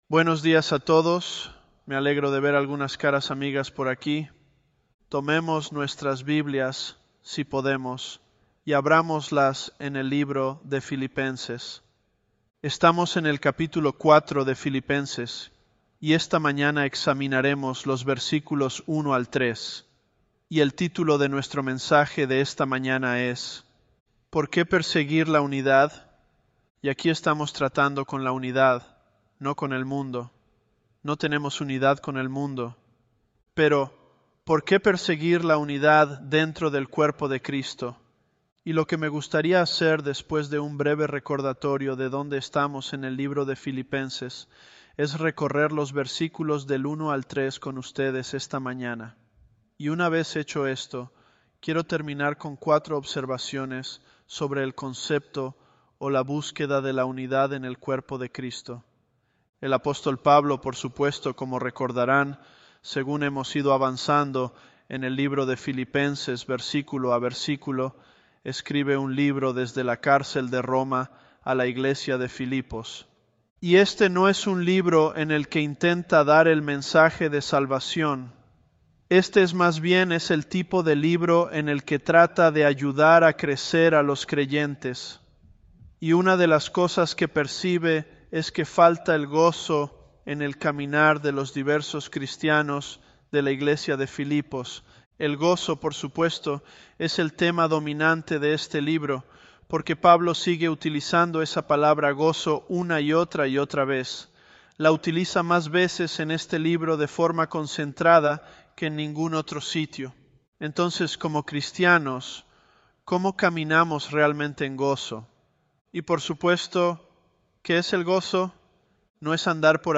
Home / Sermons / Philippians 010 - Por Qué Perseguir La Unidad?
Elevenlabs_Philippians010.mp3